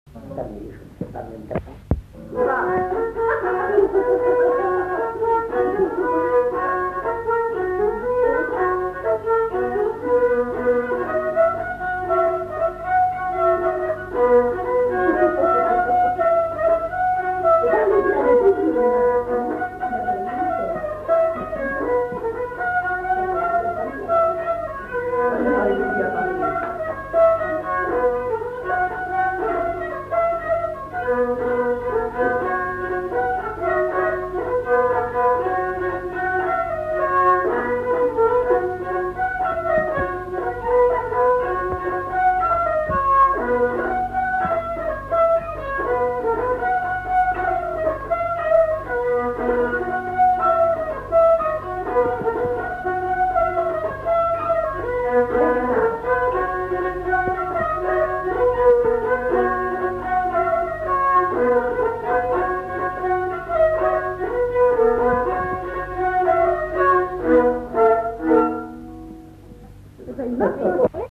Lieu : Haut-Mauco
Genre : morceau instrumental
Instrument de musique : violon
Danse : rondeau